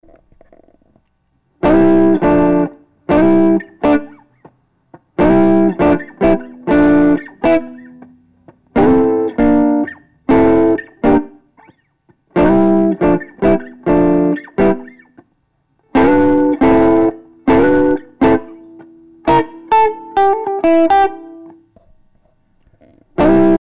I think they sound very similar.